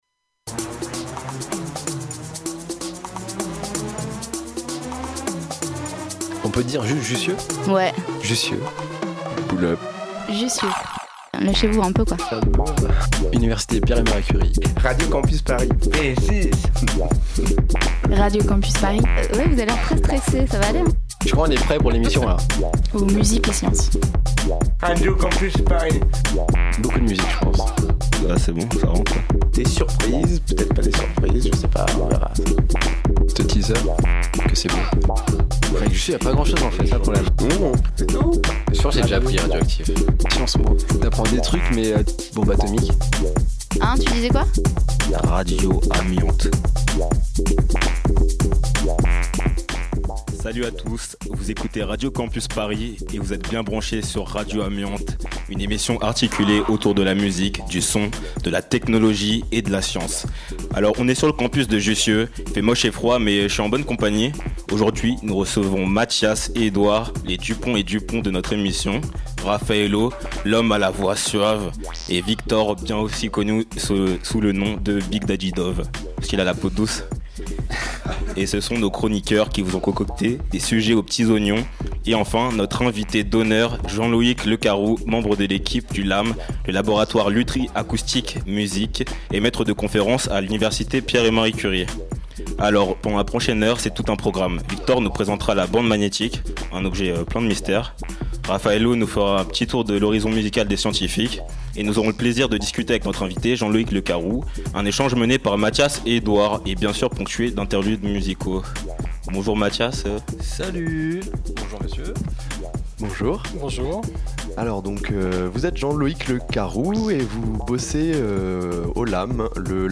Pour son dernier Tour des Facs de l'année 2015, Radio Campus Paris a posé ses valises à l'Université Pierre et Marie Curie. Après un cycle de 10 ateliers de formation aux techniques radiophoniques, les étudiants journalistes ont enregistré une émission en public dans l' Espace de Vi e Etudiante .